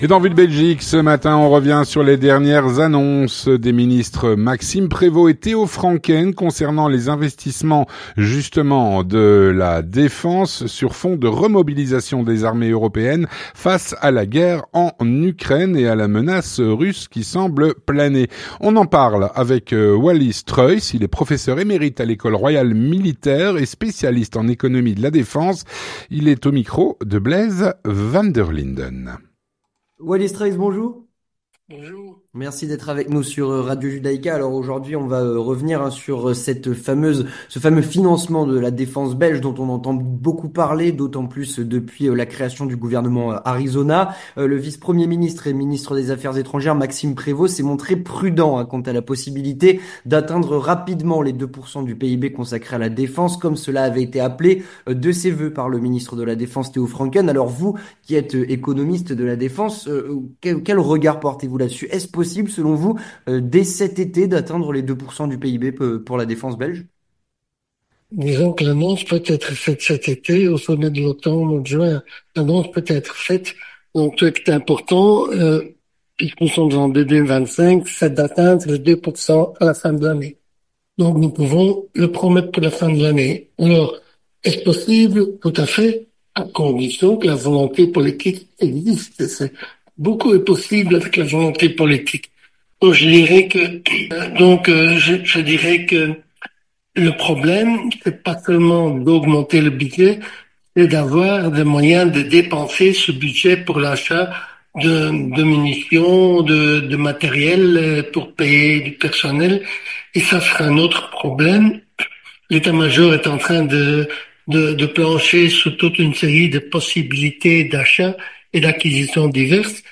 Il est au micro